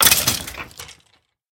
骷髅：死亡
死亡时播放此音效
Minecraft_Skeleton_death.mp3